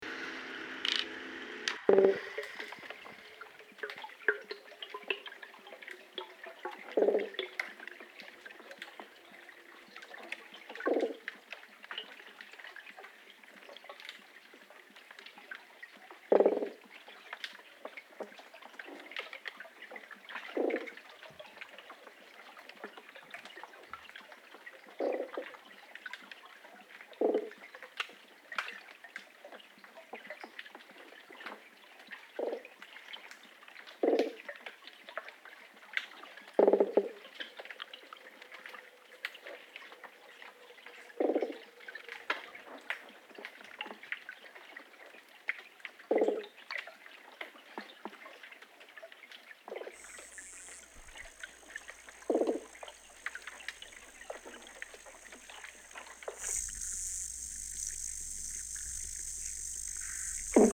Field Recording Series by Gruenrekorder
It progresses from delightful natural sounds to industrial machines slowly disrupting them.